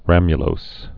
(rămyə-lōs)